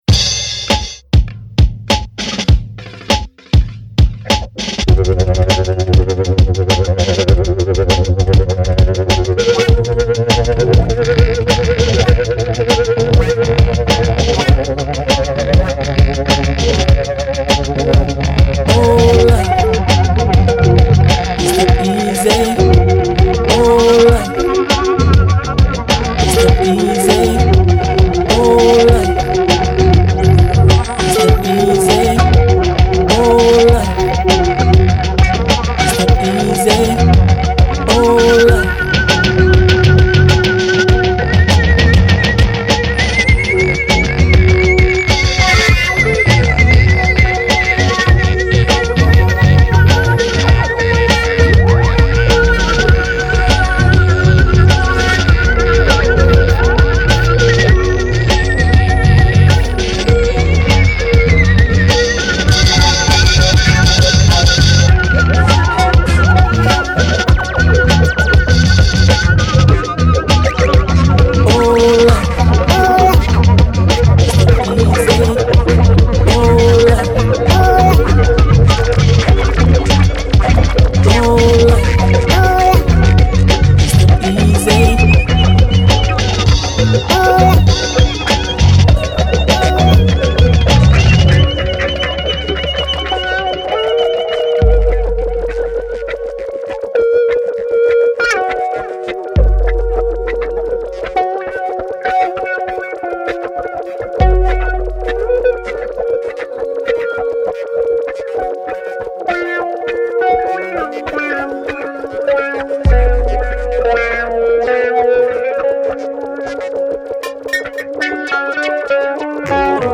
Genre: Acid-Wave.